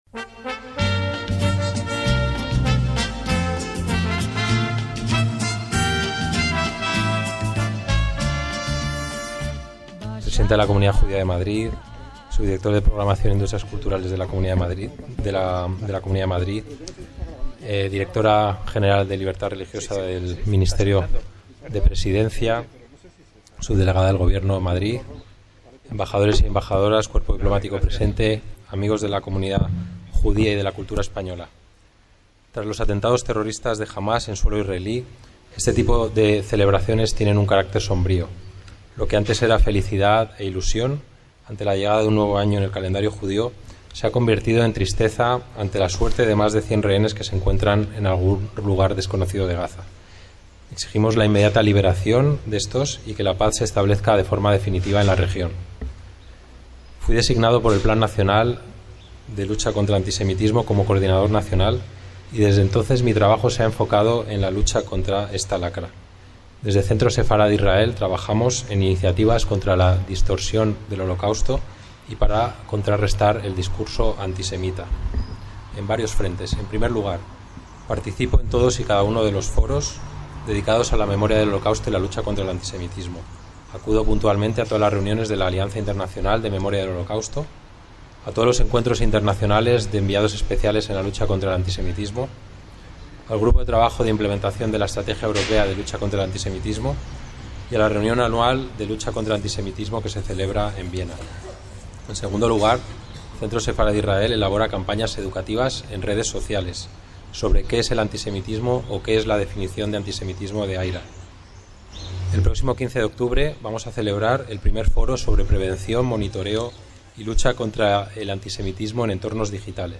ACTO EN DIRECTO - El lunes 30 de septiembre de 2024 tuvo lugar en los jardines del Centro Sefarad Israel de Madrid el acto de Celebración del Año Nuevo judío (Rosh Hashaná) 5785 y la entrega del premio Corona de Esther.